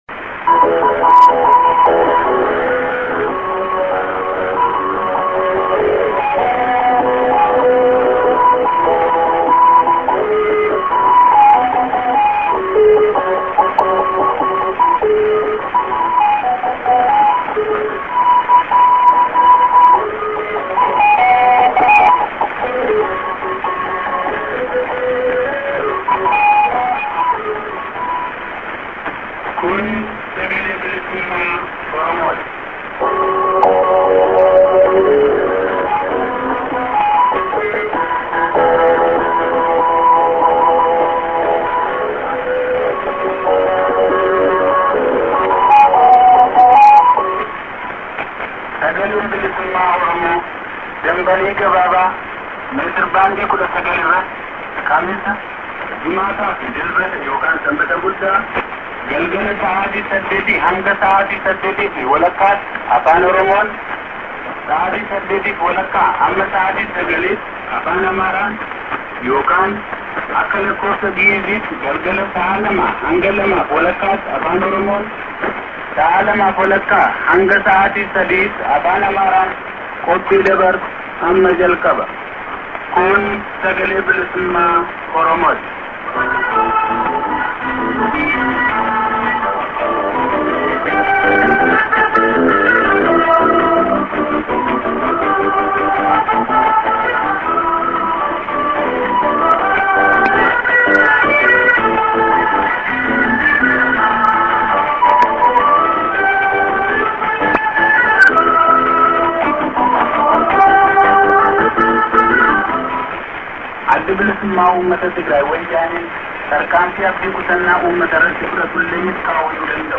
St. ST->ID(man)->music->ID(man)->